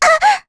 Rehartna-Vox_Damage_kr_01.wav